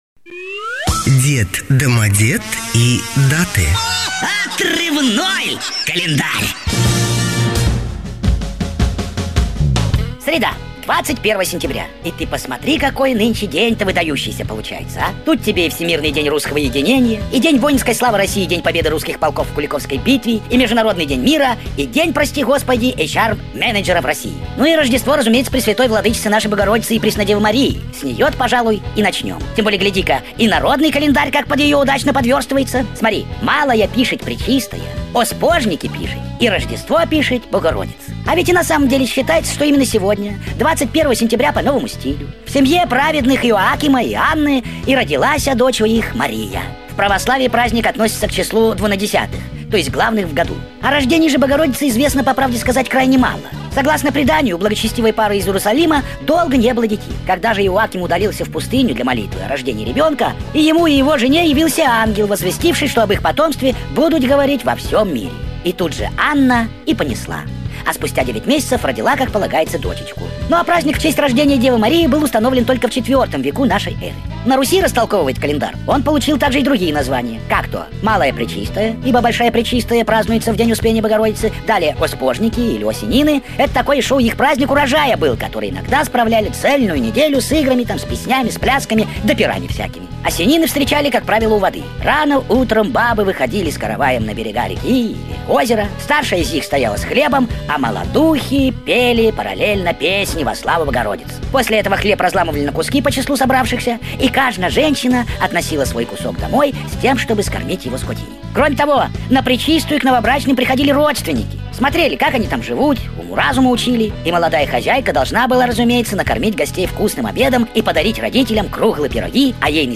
Манера подачи материала, изменённый тембр голоса, напоминает стиль старых людей.